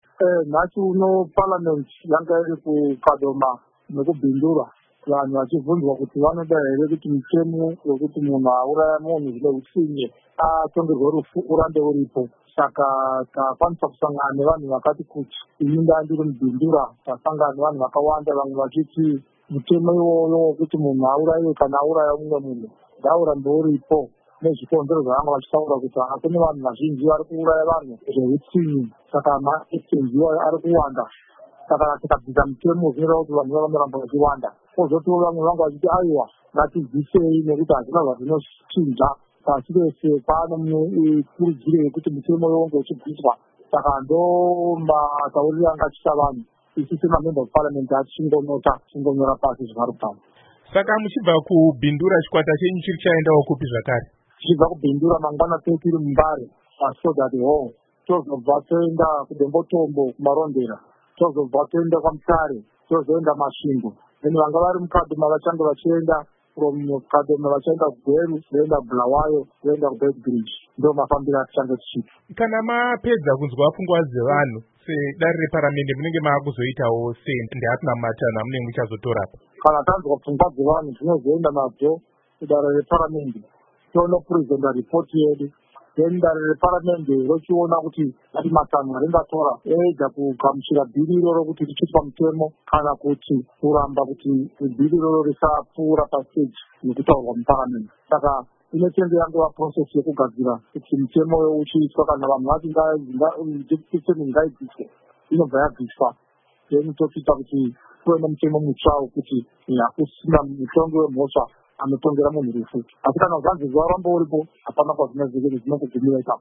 Hurukuro naVaEnergy Mutodi